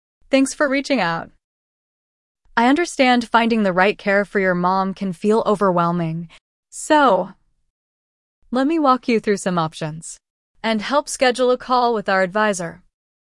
Human Like AI Voice
VerbaCall-AI-Agent-Female-1.mp3